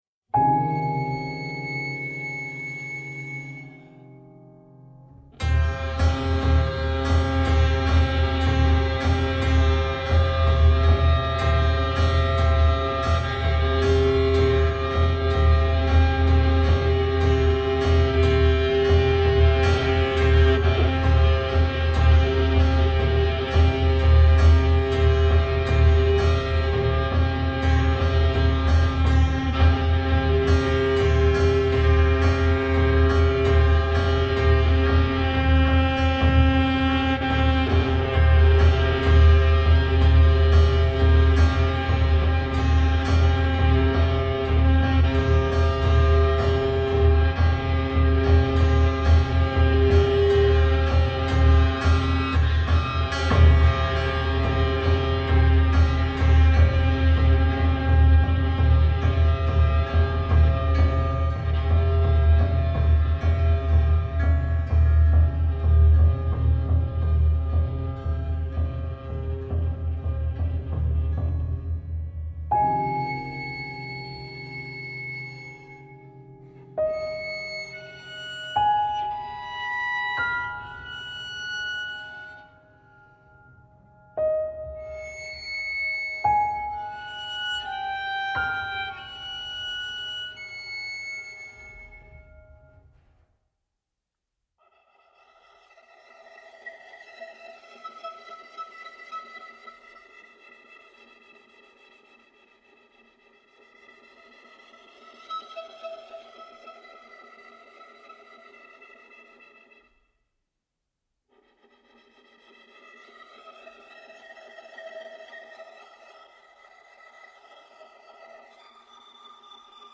• Genres: 21st Century